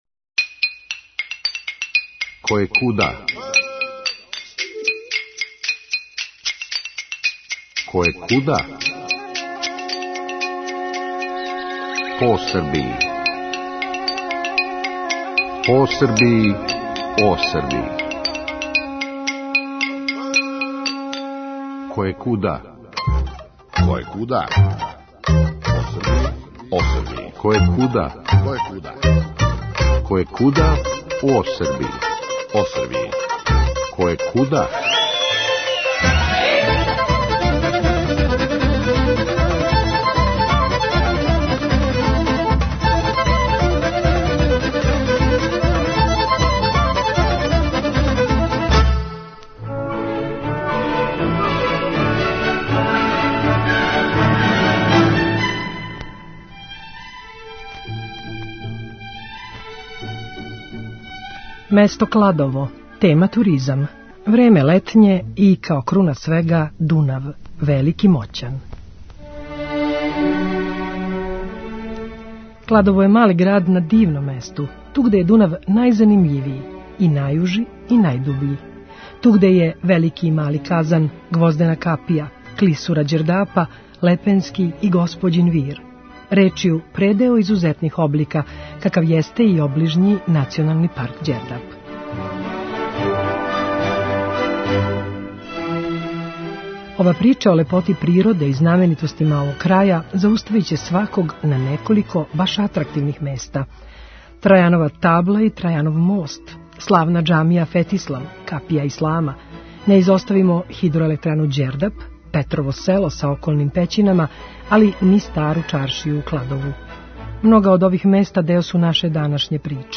забележене летос, тик поред Дунава
Леп, сунчан дан, градска плажа и река, осмех и добро расположење.